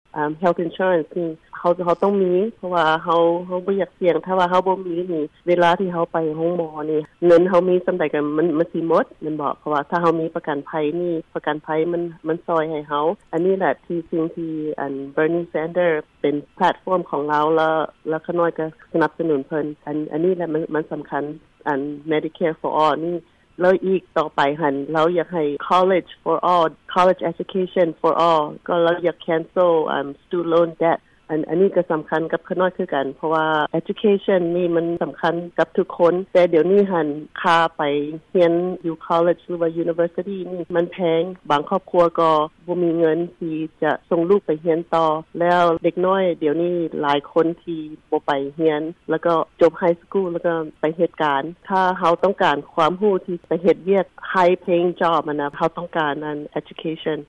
ສຽງສຳພາດ